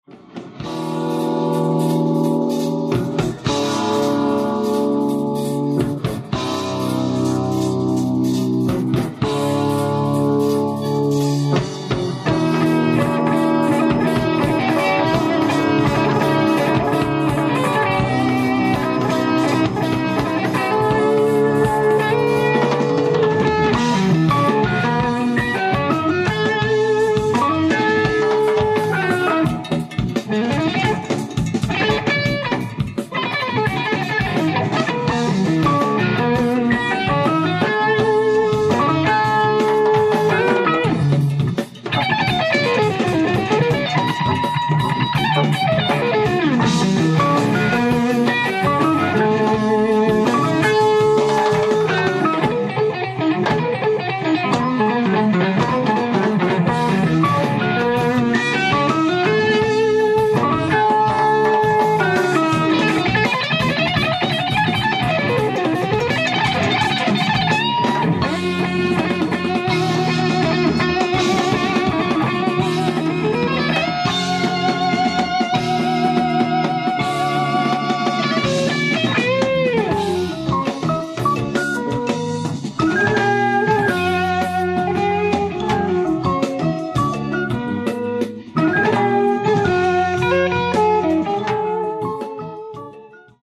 ライブ・アット・パラマウント・シアター、シアトル 05/21/1978
※試聴用に実際より音質を落としています。